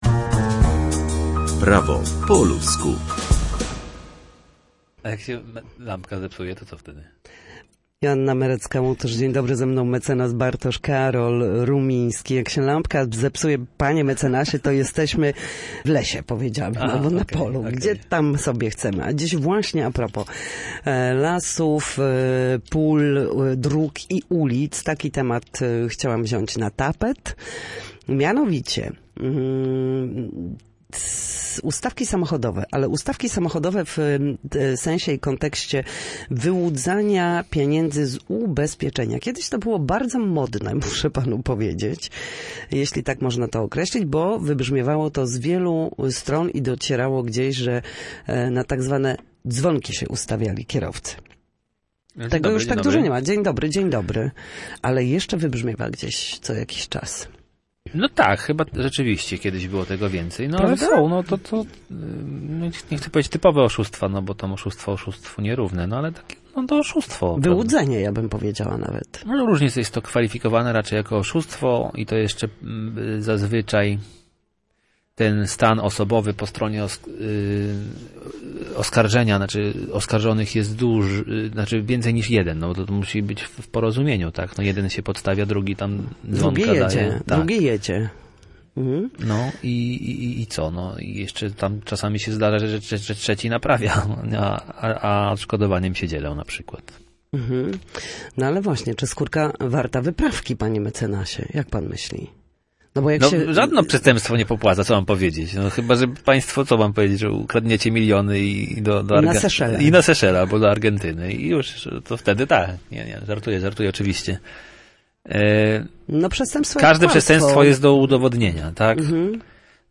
Celowe doprowadzanie do stłuczek a konsekwencje prawne. Prawnik wyjaśnia
W każdy wtorek o godzinie 13:40 na antenie Studia Słupsk przybliżamy Państwu meandry prawa. W naszym cyklu prawnym gościmy ekspertów, którzy odpowiadają na pytania związane z zachowaniem w sądzie lub podstawowymi zagadnieniami prawnymi.